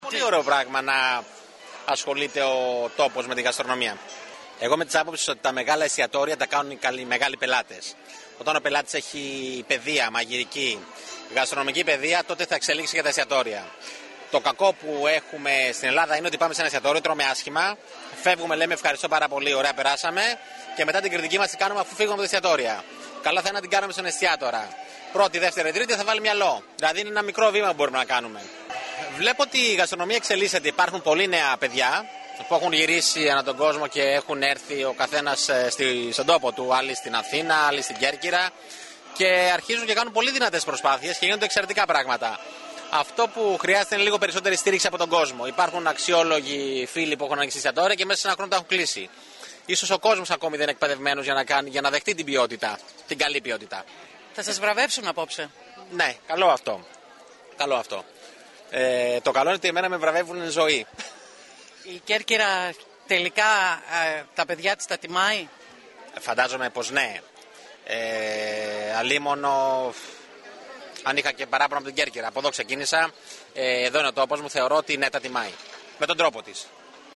Ο δήμαρχος κατά την εναρκτήρια ομιλία του επεσήμανε ότι γίνεται μια προσπάθεια  να αναδειχτεί η τοπική γαστρονομία που χρησιμοποιεί υλικά τα οποία δεν υπάρχουν σε κανένα άλλο τόπο στην Ελλάδα.
Κατά την έναρξη  του φεστιβάλ βραβεύτηκε ο γνωστός Κερκυραίος σεφ Έκτορας Μποτρίνι ο οποίος μιλώντας στην ΕΡΤ Κέρκυρας χαρακτήρισε πολύ θετικό να ασχολείται ένας τόπος με την γαστρονομία  επισημαίνοντας ότι στο νησί η γαστρονομία εξελίσσεται με γρήγορους ρυθμούς αν και ο  κόσμος δεν έχει ακόμα εκπαιδευτεί για να δεχτεί το ποιοτικό φαγητό.